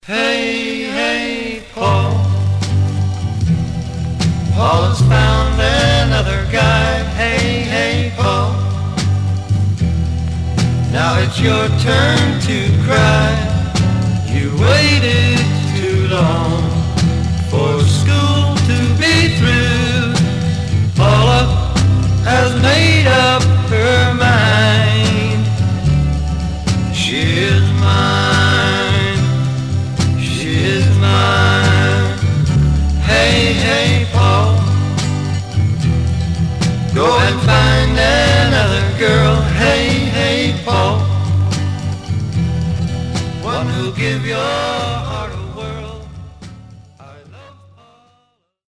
(Duet)
Some surface noise/wear
Mono